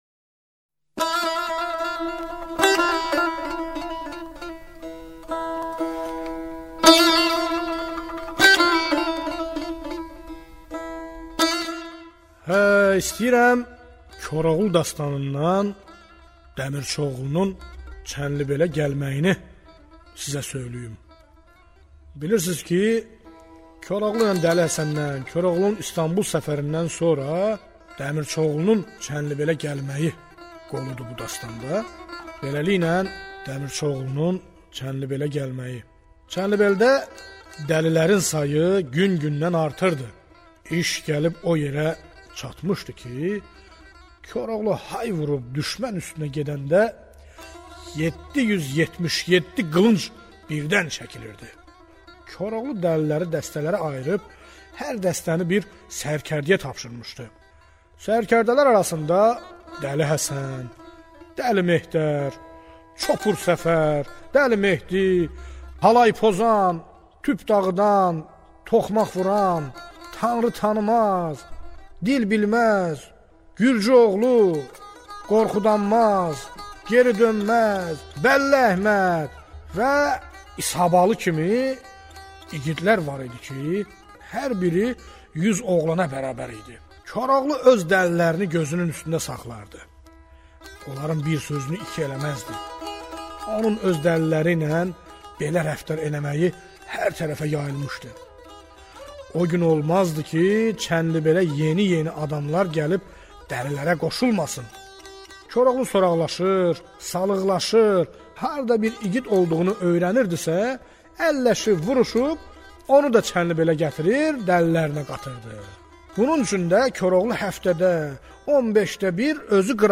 Azeri dastan